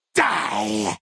10 KB Category:Fallout: New Vegas creature sounds 1
FNV_GenericFeralGhoulAttack_Die.ogg